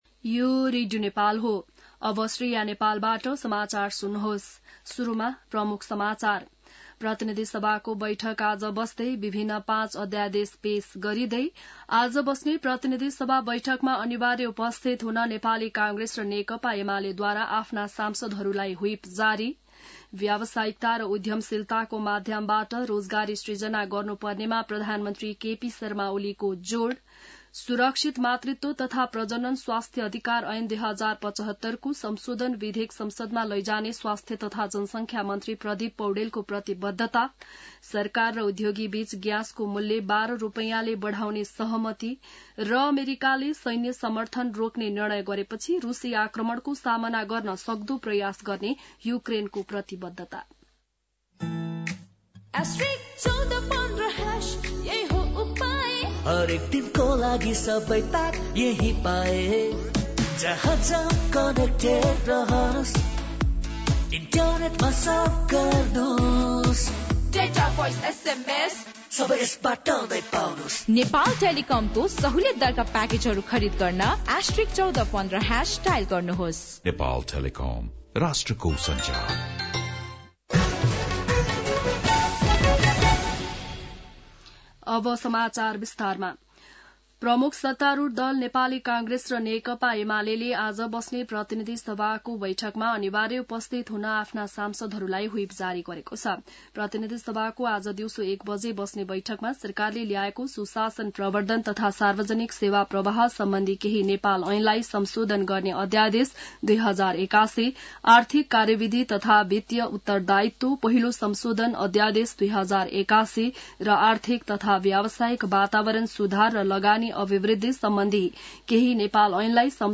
बिहान ७ बजेको नेपाली समाचार : २२ फागुन , २०८१